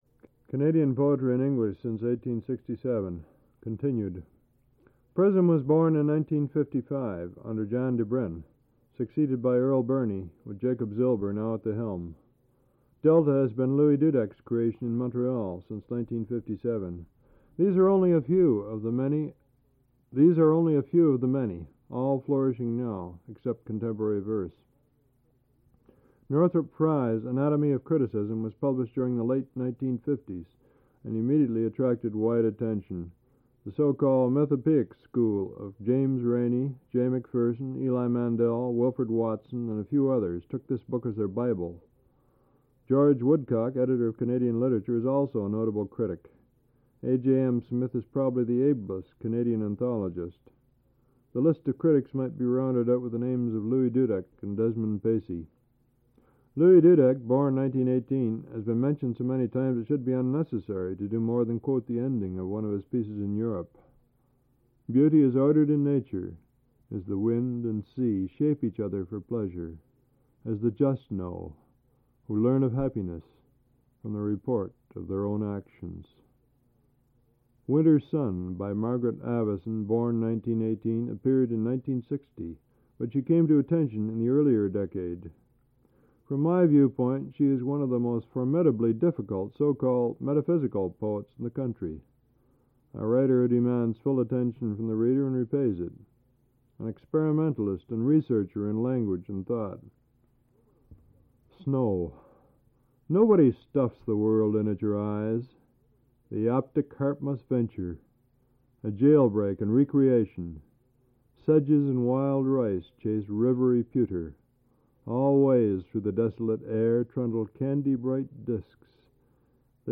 Al Purdy Reads an essay and a review.
1/4"' Reel-to-Reel Tape